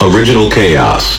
完美适用于打造震撼的低音、丰富的节奏纹理和地下音乐氛围。